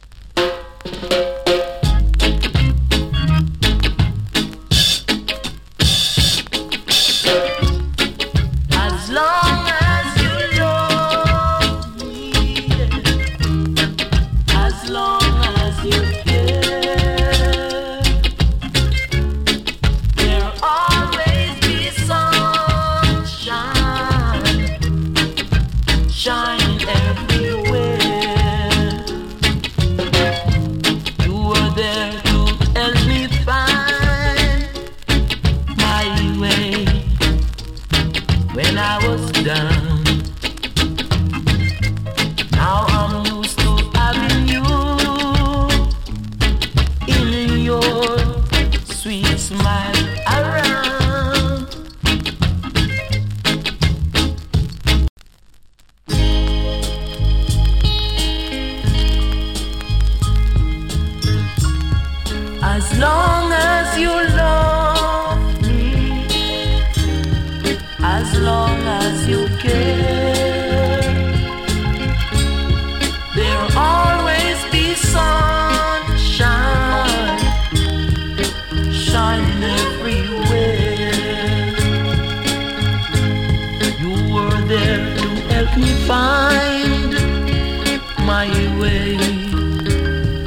＊スリキズ少々有り。チリ、パチノイズ少し有り。
NICE VOCAL REGGAE !